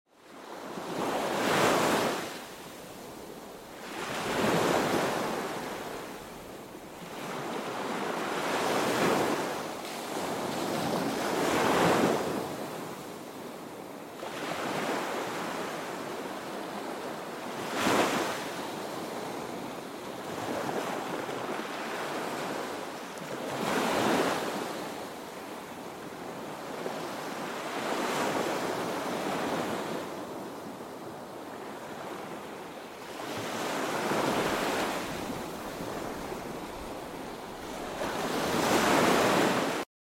Ambience Ocean Waves Relaxation #4k sound effects free download